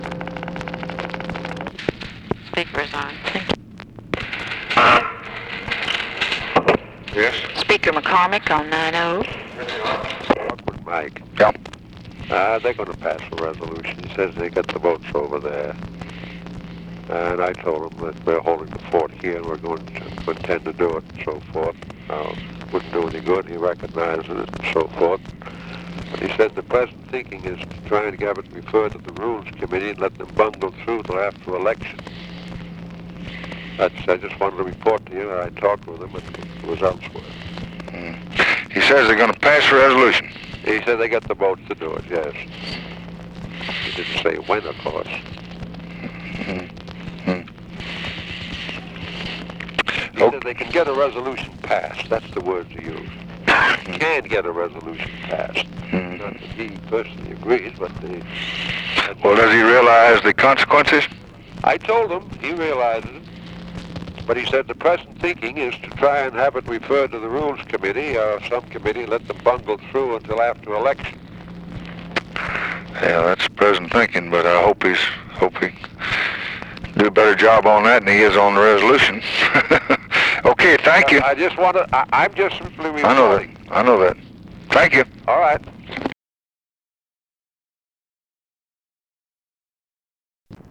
Conversation with JOHN MCCORMACK, September 8, 1964
Secret White House Tapes